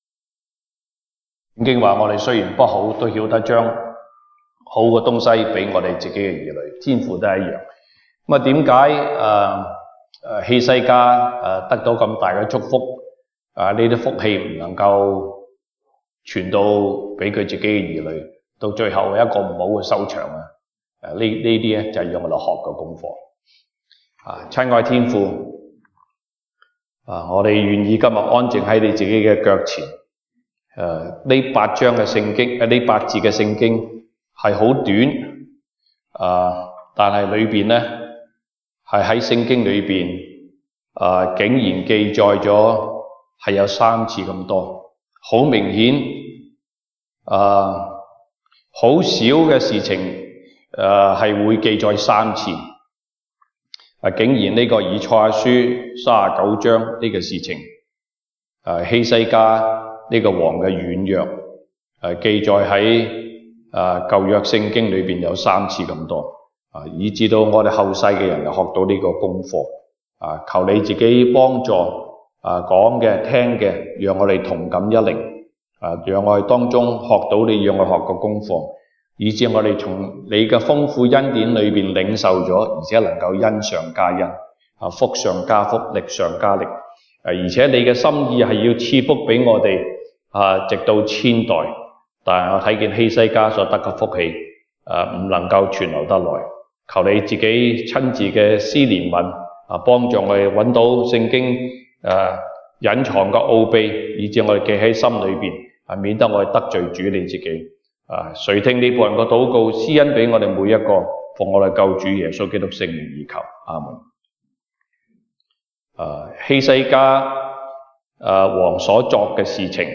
東北堂證道 (粵語) North Side: 從恩典中墜落